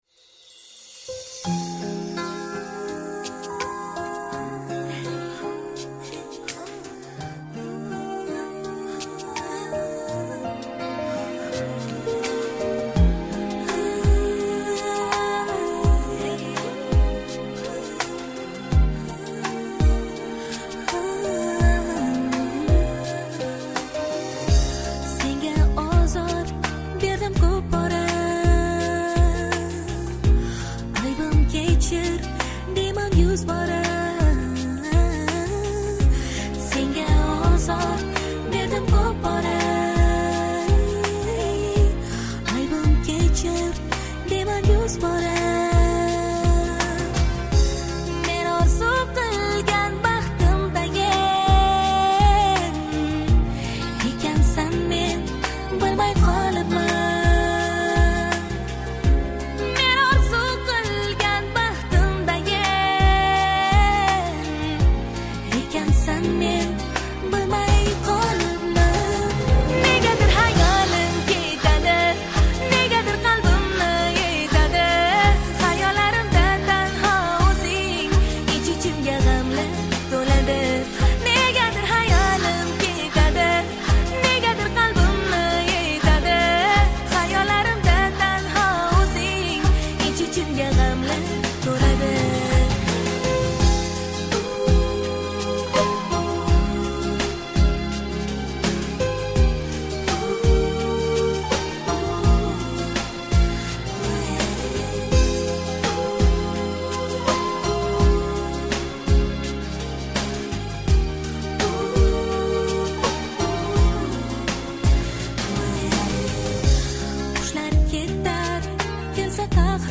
uzbekskaya_pesnya_pro_lyubov__medlennaya_i_ochen_krasivaya.mp3